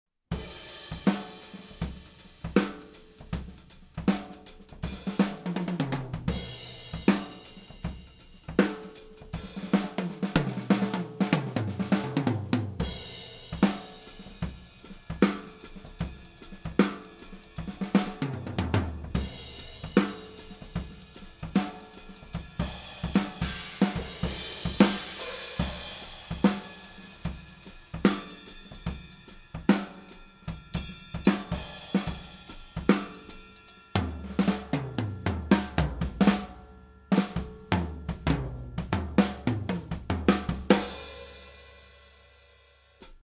Das rechte Mikro war ca. 15cm über dem Standtom auf die Hihat schauend ausgerichtet.
Das linke Mikro war direkt von oben genau zwischen die Snare und die Bassdrum schauend ausgerichtet.
Die Signale der Overheads gingen in ein Soundcraft-Mischpult, EQs flat, Panning ca. 8:00 und 16:00 Uhr.
Der Raum ist ein zu ca. 2/3 mit Noppenschaum ausgekleideter Kellerraum von vielleicht 3m x 5m. Die Deckenhöhe ist leider nicht groß und wird durch ein ca. 20cm hohes Drumpodest noch weiter verringert - nicht ideal für Overhead-Aufnahmen. Überhaupt ist der Raum zwar akustisch zum Proben ganz gut, aber für Aufnahmen eigentlich zu trocken und in den Höhen recht gedämpft. Ich war überrascht, das die Technik trotzdem so gut funktioniert hat...
e664 - nur Overheads - ganzes Set - flat
Glyn-Johns_e664_OH-solo_ganzes-Set_flat.MP3